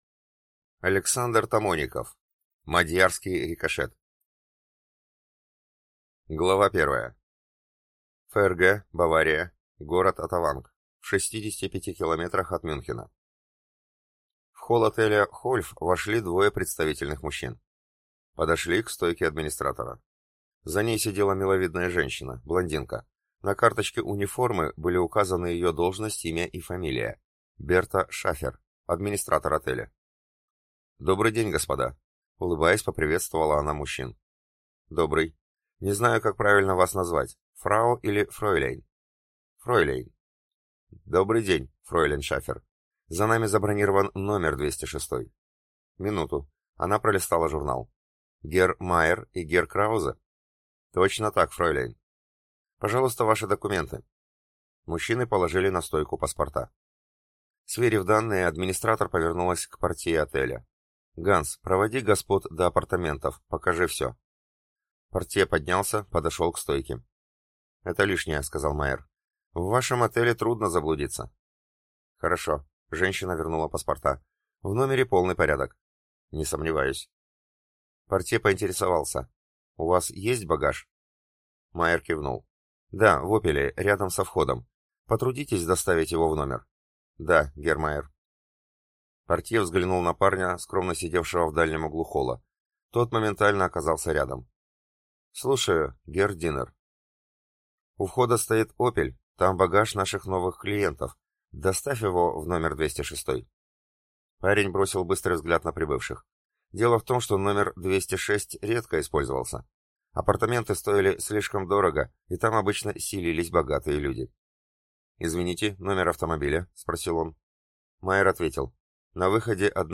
Аудиокнига Мадьярский рикошет | Библиотека аудиокниг